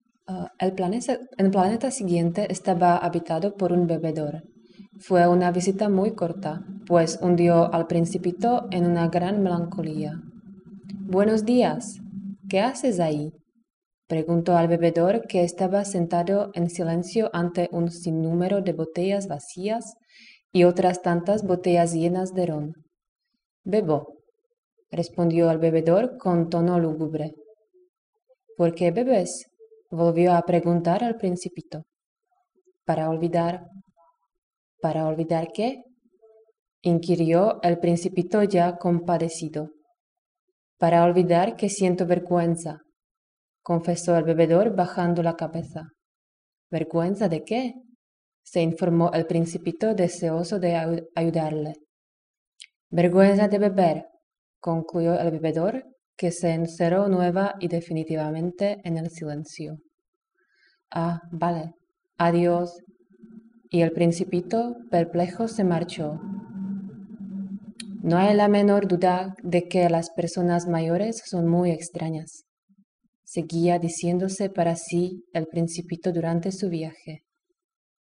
L2 Spanish with L1 Slovak (F, C1):
slovak.wav